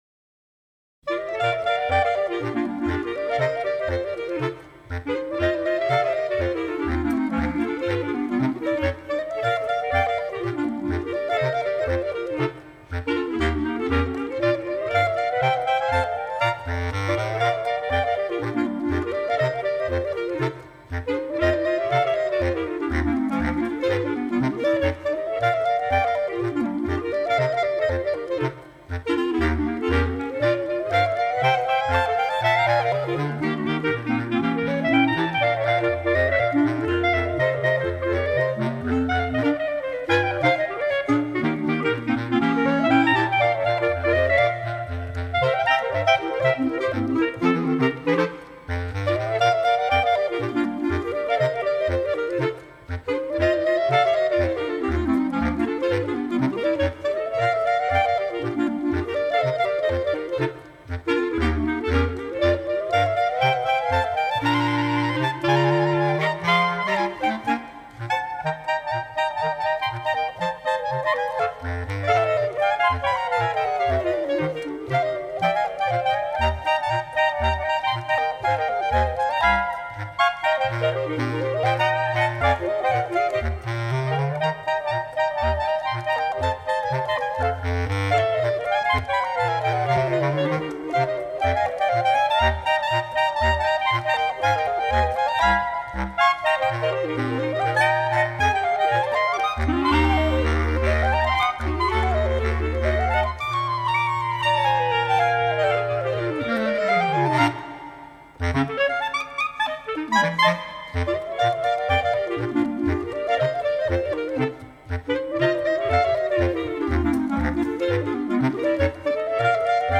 Scored for 3 Bb Clarinet, and 1 Bass Clarinet.
(Wiki: Ragtime)